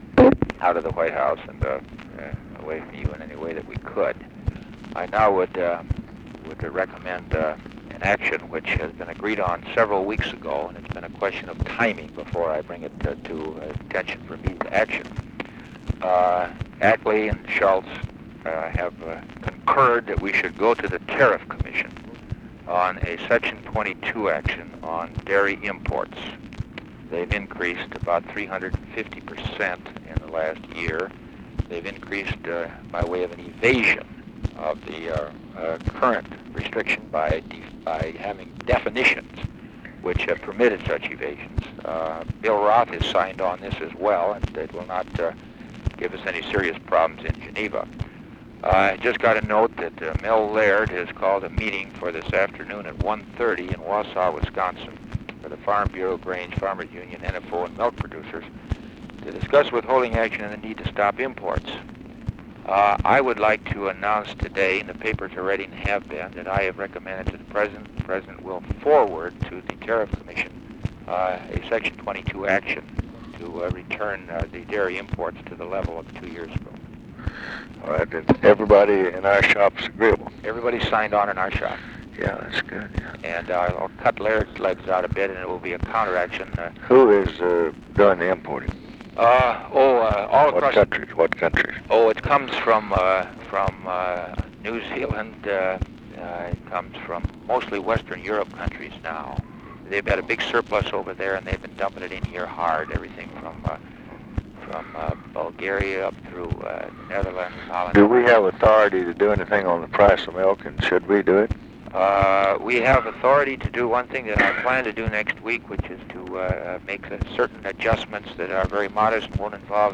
Conversation with ORVILLE FREEMAN, March 30, 1967
Secret White House Tapes